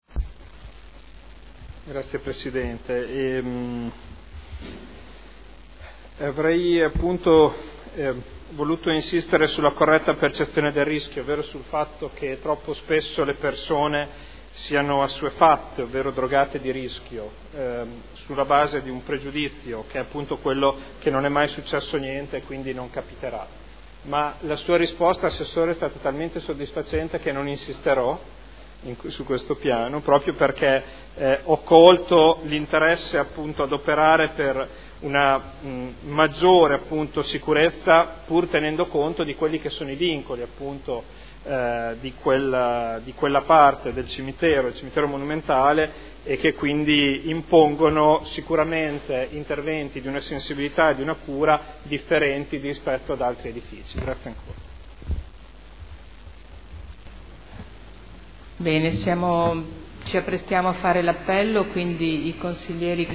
Seduta del 03/12/2012. Conclude interrogazione del consigliere Ricci (Sinistra per Modena) avente per oggetto: “Cimitero S. Cataldo”